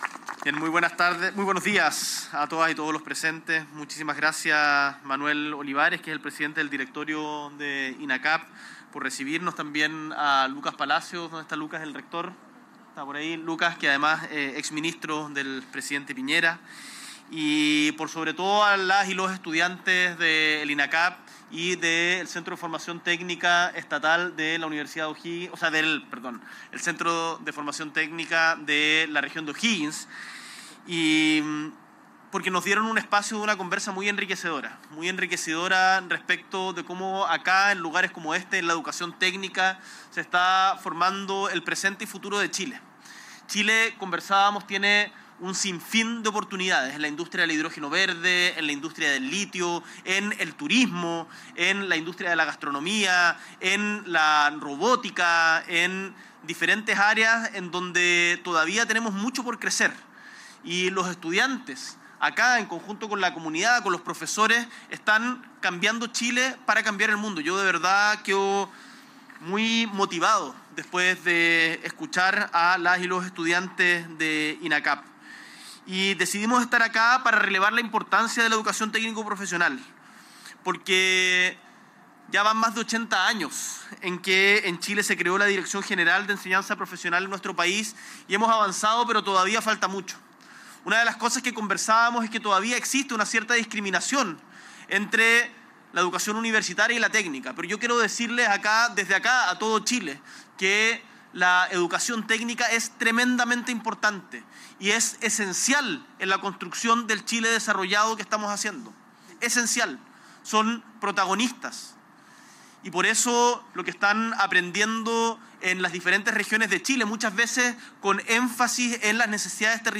S.E. el Presidente de la República, Gabriel Boric Font, encabeza el conversatorio estudiantil "Construyendo Futuro Laboral: Oportunidades para el Empleo Joven"